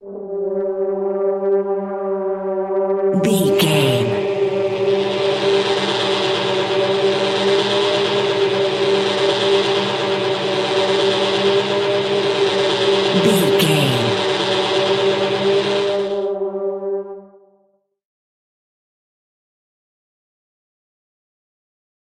In-crescendo
Thriller
Atonal
ominous
suspense
eerie
Horror Synths
atmospheres